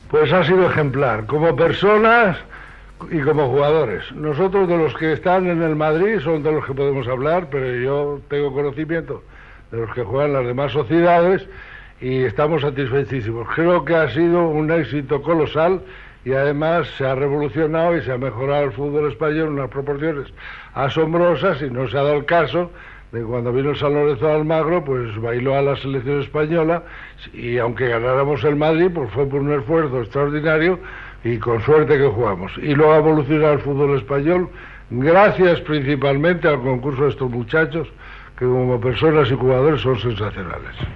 Esportiu
Fragment extret del programa "Audios para recordar" de Radio 5 emès el 30 d'abril del 2018.